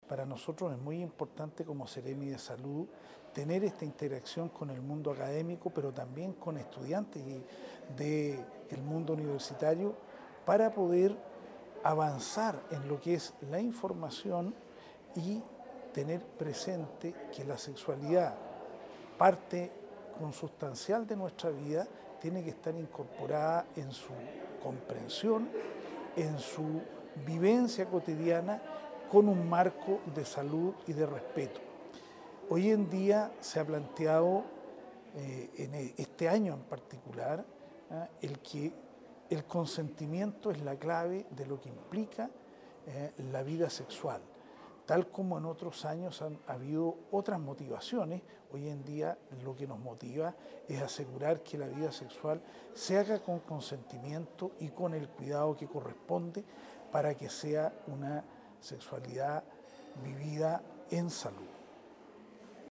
Este año nuestro objetivo se centra en la importancia del consentimiento ante cualquier encuentro sexual saludable, además de relevar lo indispensable que es reducir riesgos de infecciones de transmisión sexual, mediante el uso del preservativo”, declaró el Seremi de Salud del Biobío, Dr. Eduardo Barra Jofré.